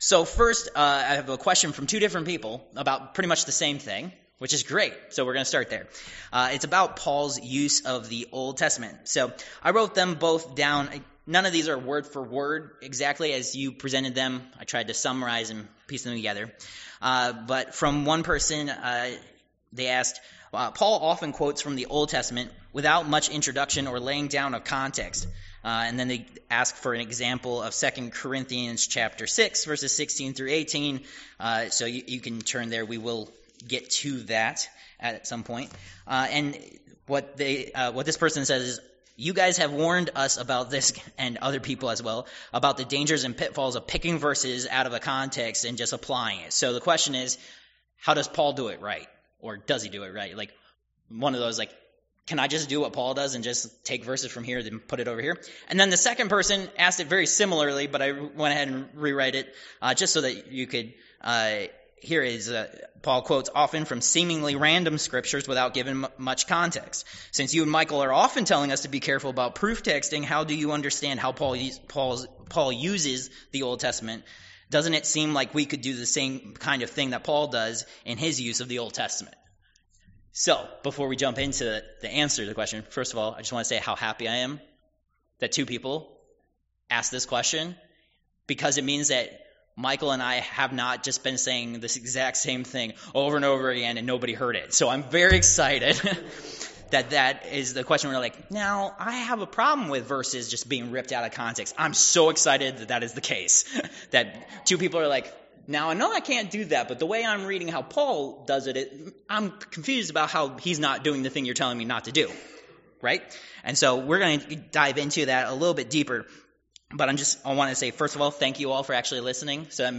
2 Corinthians Service Type: Worship Service « Protected